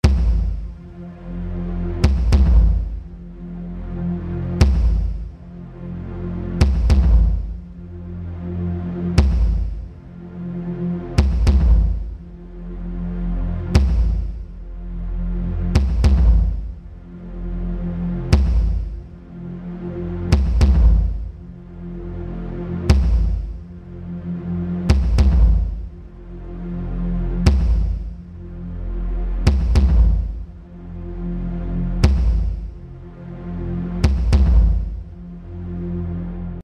Im letzten Audiodemo habe ich für das Mittelsignal nur das tiefe Band verwendet (und dieses per Sidechain komprimiert); für das Seitensignal habe ich im Gegenzug das tiefe Band ausgeschaltet und das mittlere und hohe Band komprimiert.